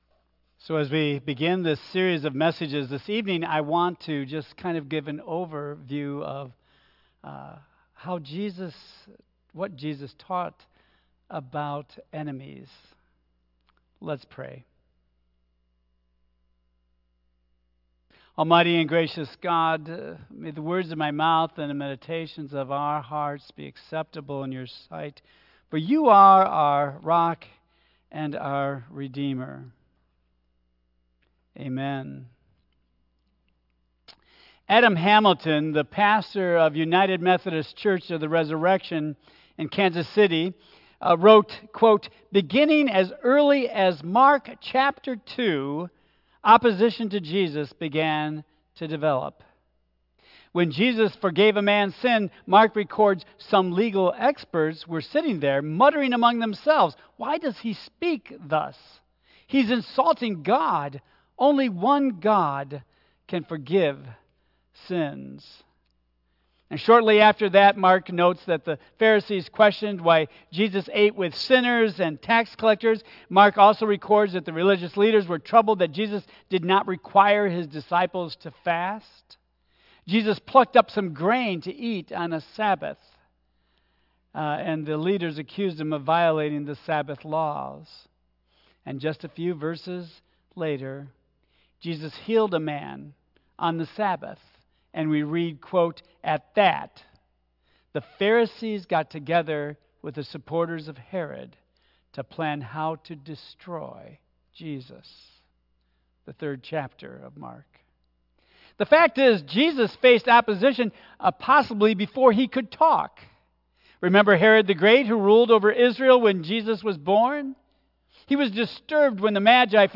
Tagged with Ash Wednesday , Central United Methodist Church , Lent , Michigan , Sermon , Worship Audio (MP3) 7 MB Previous The Attitude of Faith Next The Trial of Temptation